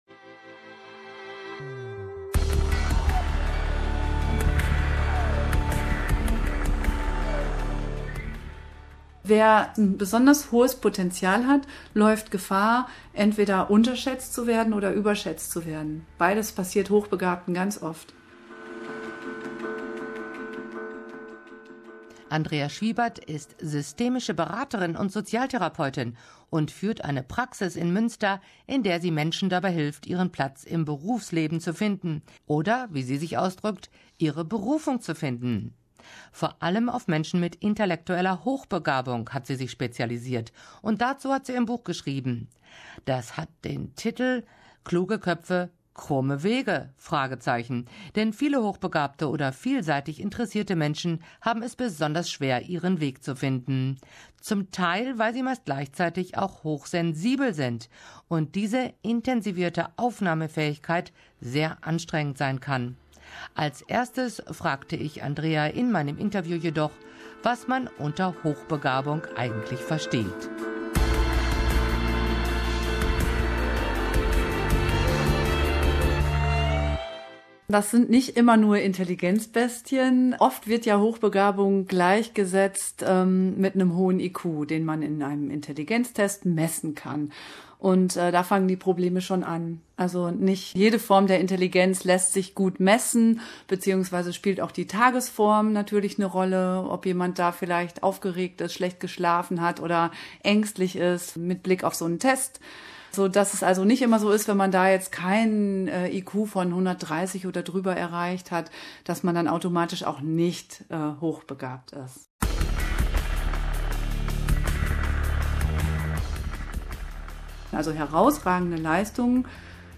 Außerdem kommen zwei Frauen zu Wort